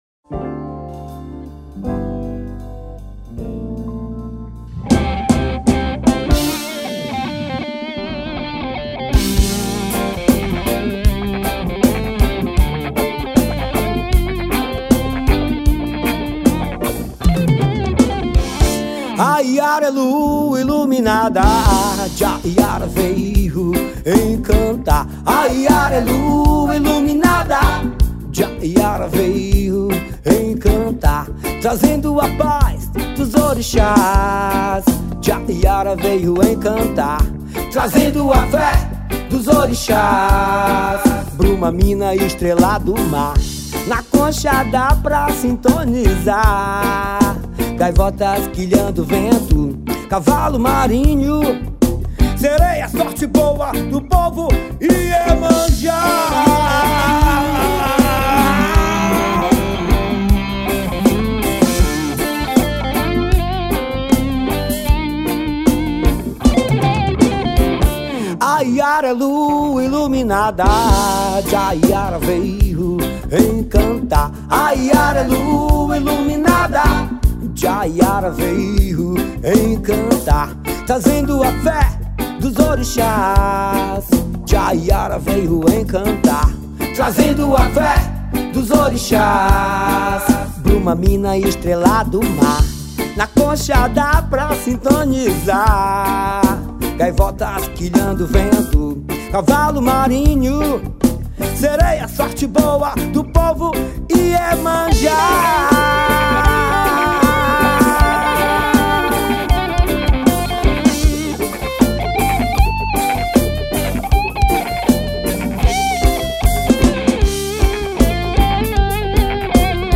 2240   02:28:00   Faixa:     Forró